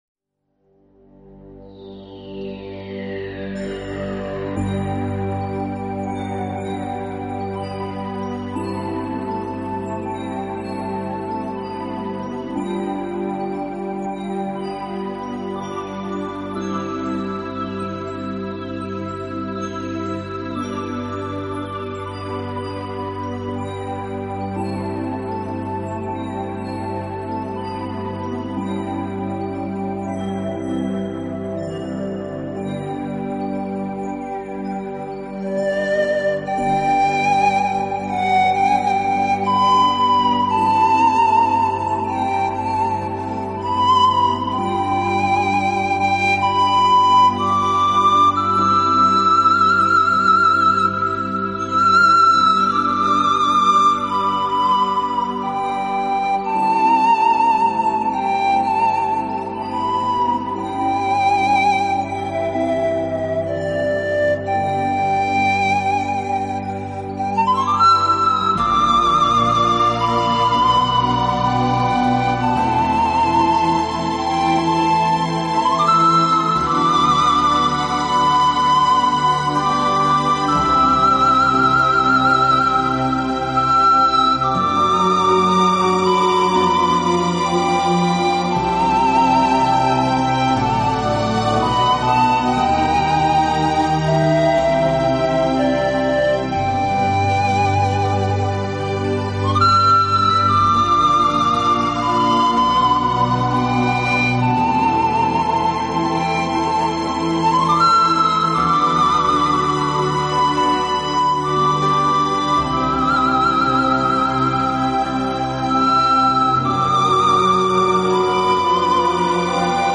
Pan Flute